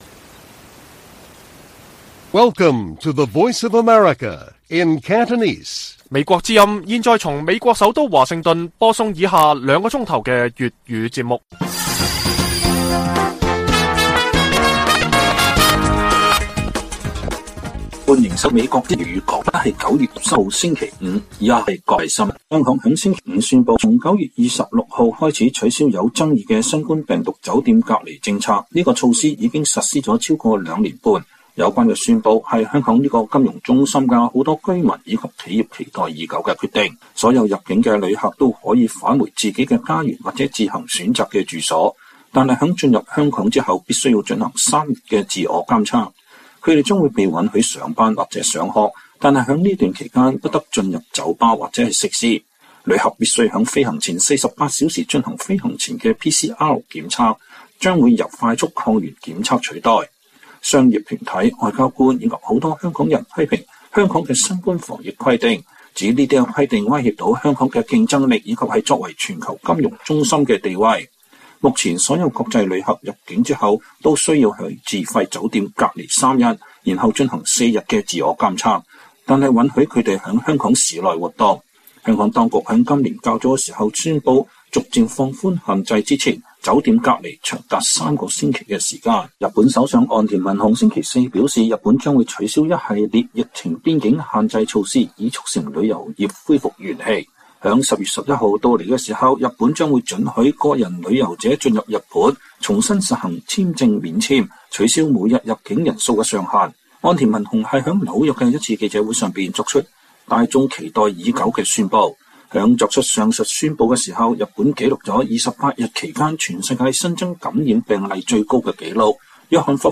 粵語新聞 晚上9-10點: 香港宣布取消旅客到港的新冠隔離政策